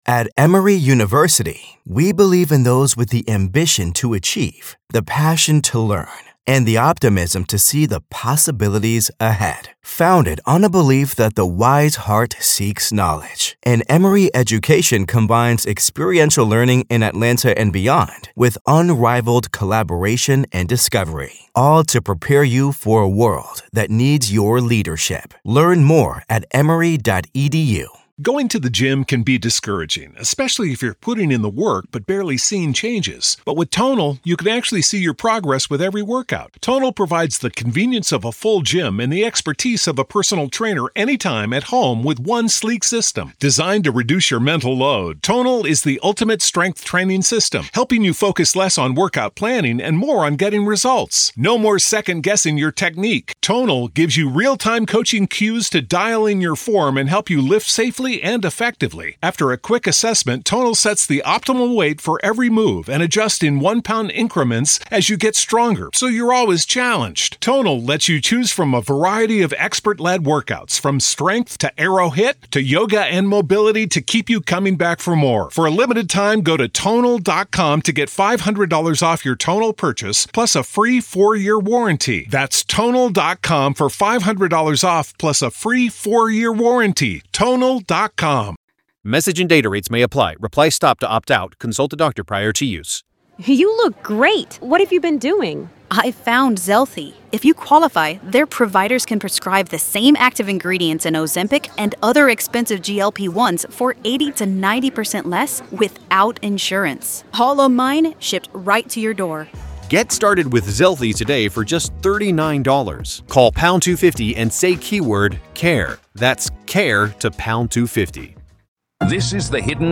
Could ANYONE Have Stopped Bryan Kohberger? Ret FBI Special Agent Answers